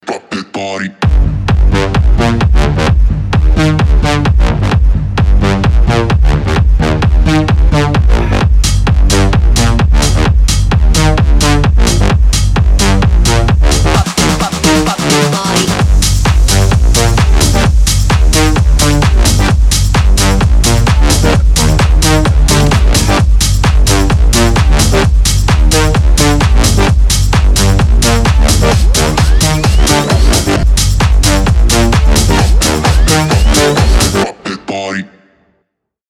громкие
мощные
EDM
Bass House
энергичные